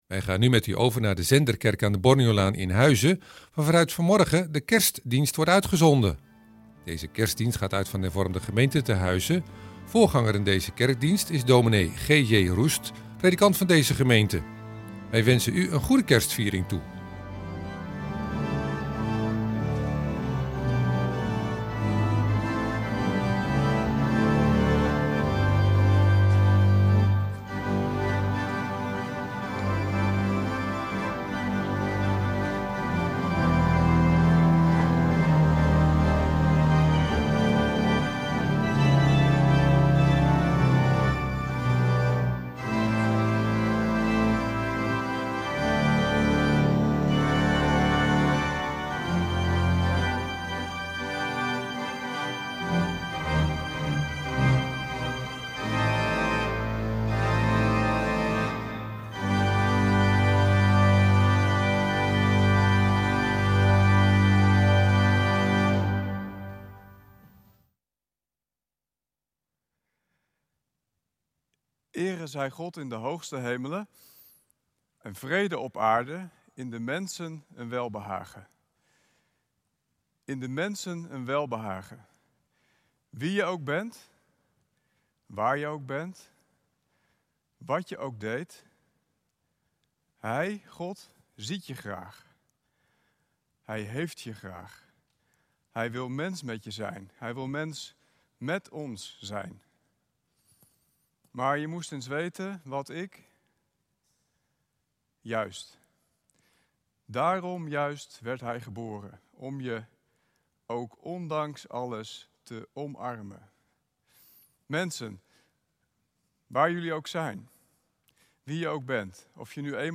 Op 1e Kerstdag vanuit de Zenderkerk in Huizen een kerkdienst.